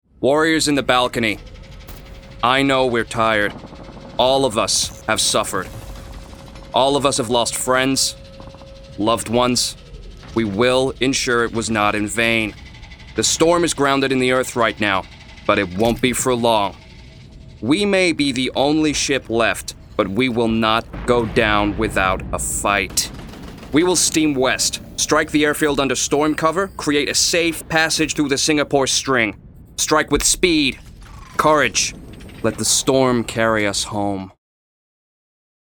• Male
US Commander. Audio Book, Character, Dramatic